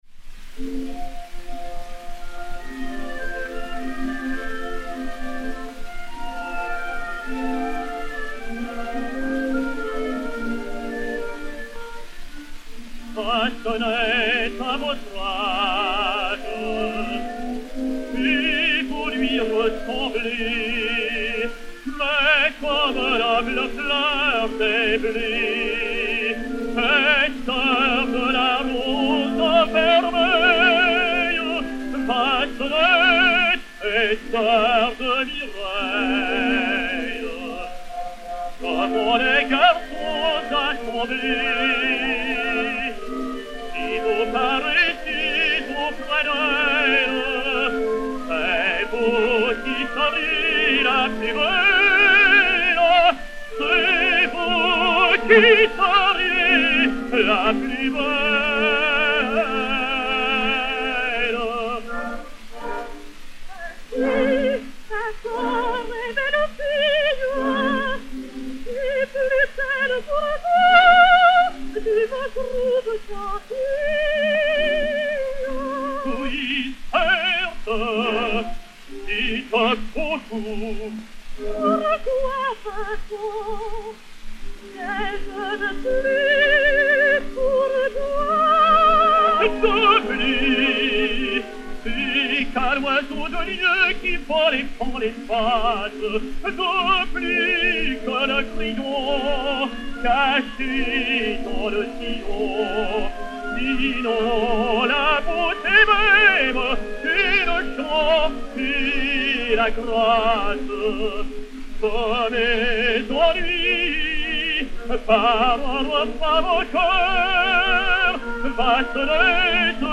Rose Heilbronner (Marguerite), Léon Beyle (Faust), Paul Payan (Méphistophélès) et Orchestre